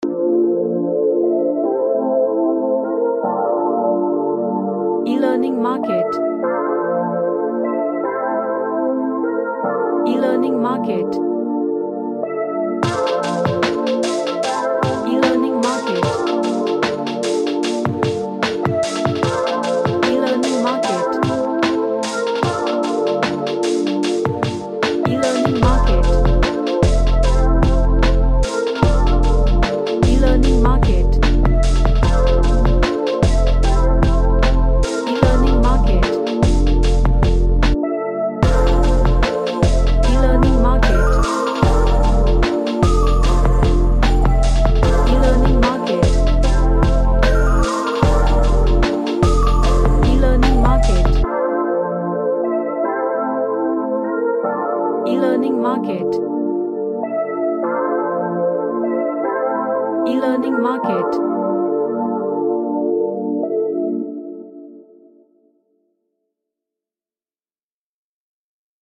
A gentle vinyl track
Gentle / Light